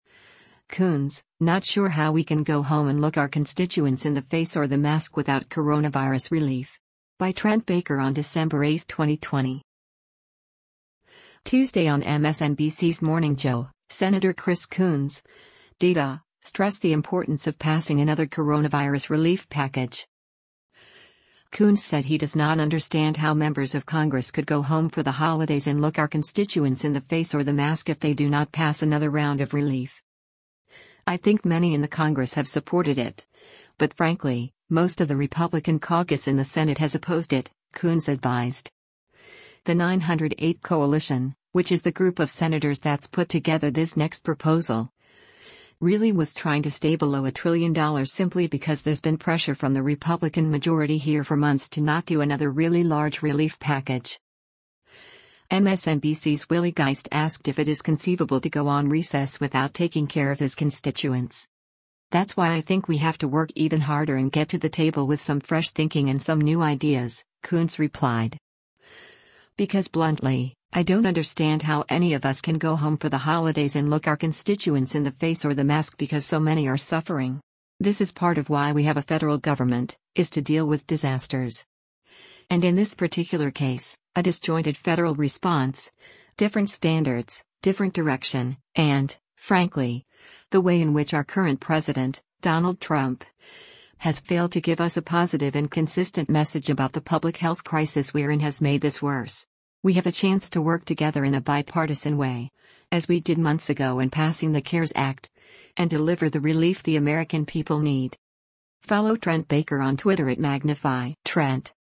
Tuesday on MSNBC’s “Morning Joe,” Sen. Chris Coons (D-DE) stressed the importance of passing another coronavirus relief package.
MSNBC’s Willie Geist asked if it is conceivable to go on recess without taking care of his constituents.